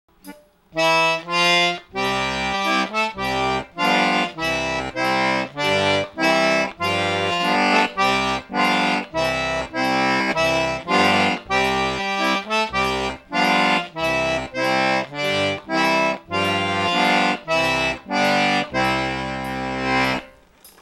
The right hand left hand independence can be challenging!
wafus_both.wma